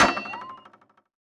otherLongJump.wav